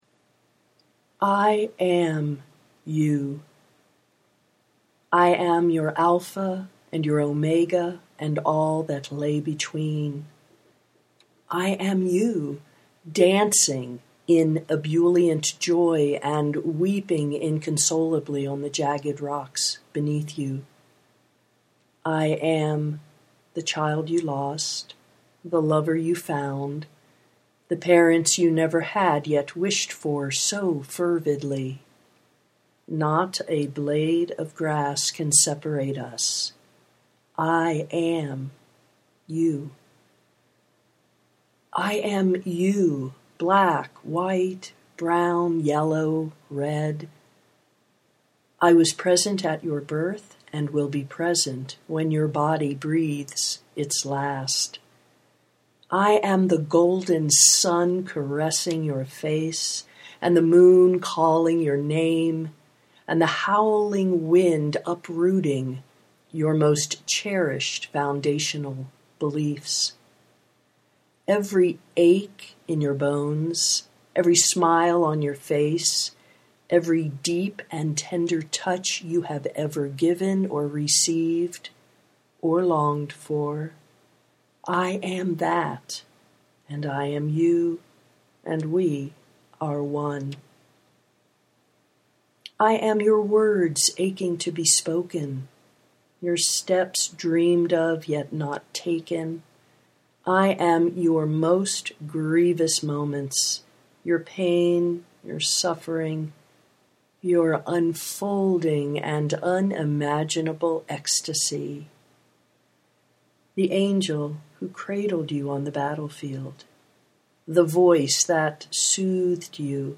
i am you (audio poetry 3:26)